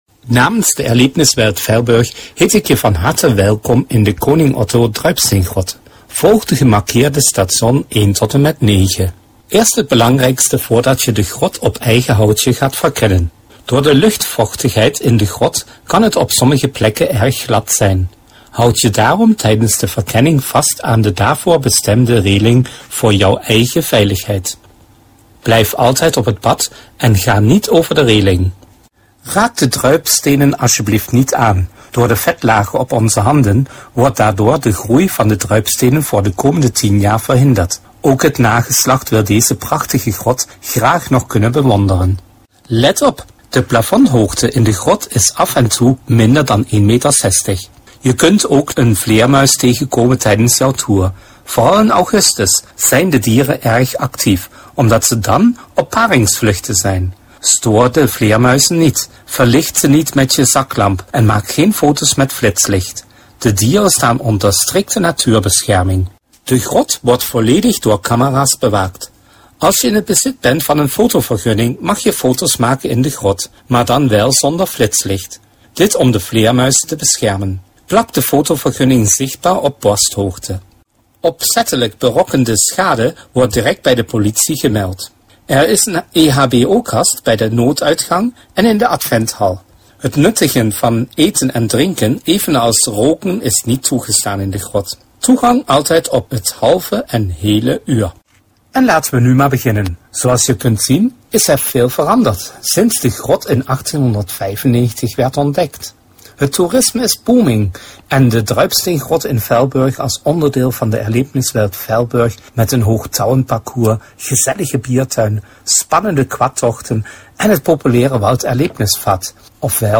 Audio Guide Deutsch